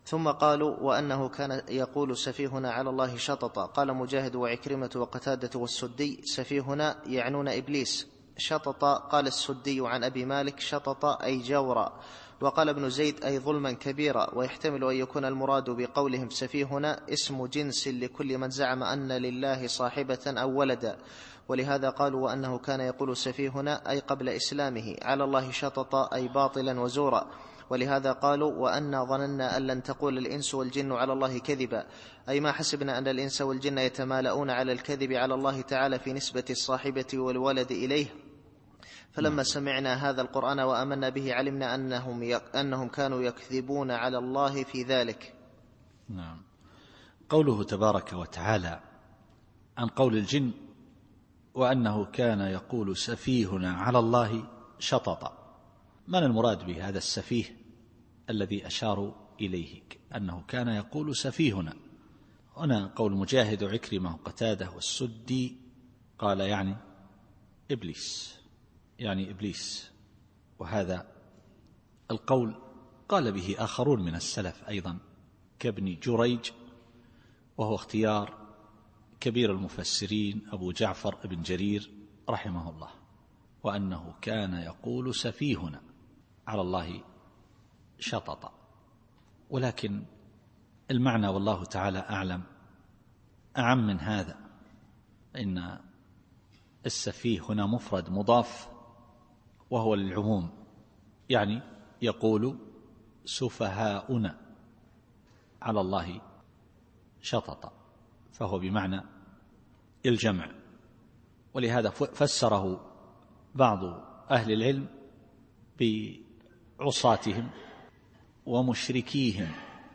التفسير الصوتي [الجن / 4]